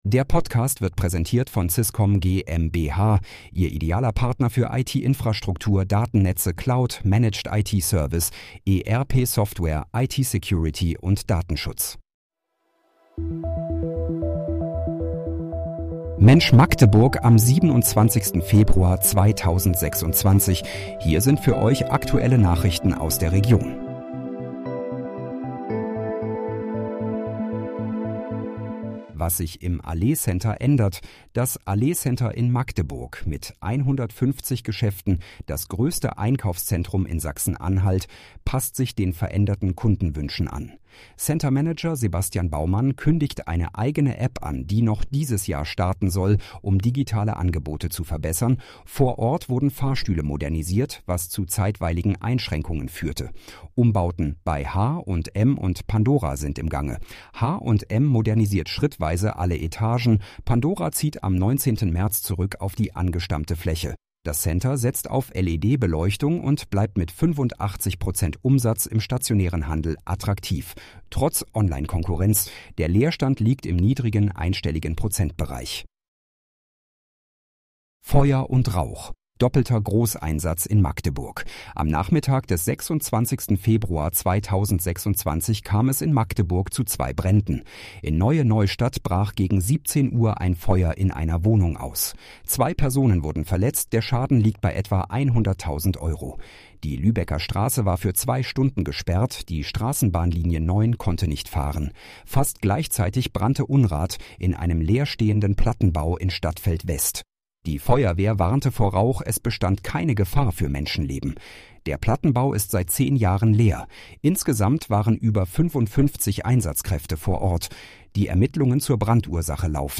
Mensch, Magdeburg: Aktuelle Nachrichten vom 27.02.2026, erstellt mit KI-Unterstützung